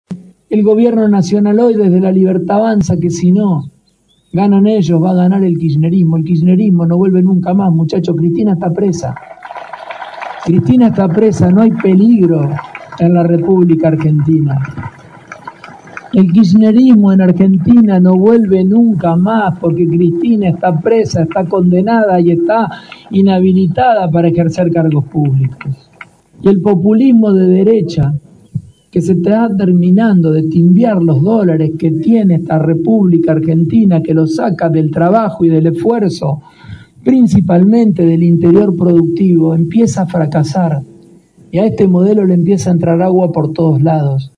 La capital provincial fue escenario este miércoles del lanzamiento oficial de la campaña de Provincias Unidas en Santa Fe, el frente político que reúne a seis gobernadores del interior del país y que busca consolidarse en las elecciones legislativas del 26 de octubre como alternativa a la polarización entre el kirchnerismo y el oficialismo de Javier Milei.
El móvil LT3 en la ciudad capital de la provincia, tomó parte de los testimonios del gobernador de Santa Fe Maximiliano Pullaro, quien dijo que «El populismo de derecha y el de izquierda fracasaron. Provincias Unidas es la alternativa de la producción, de la industria, de la minería y de la energía. Es el proyecto federal que necesita la Argentina».